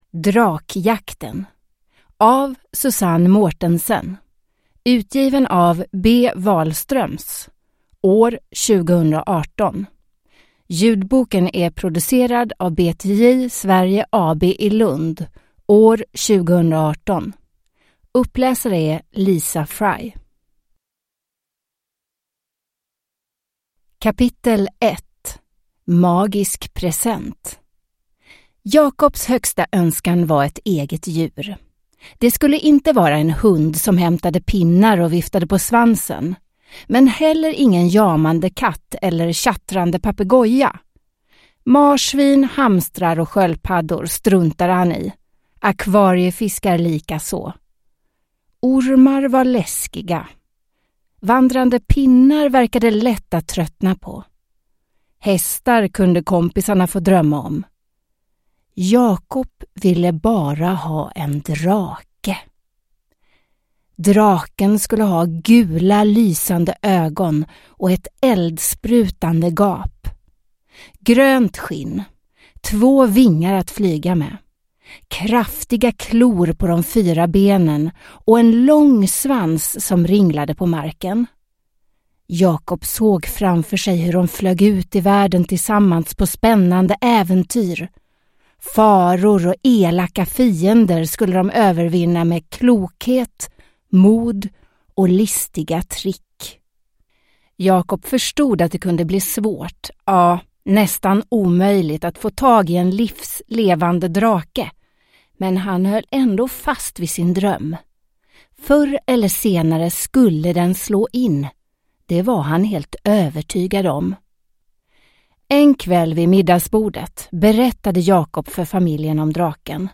Drakjakten – Ljudbok – Laddas ner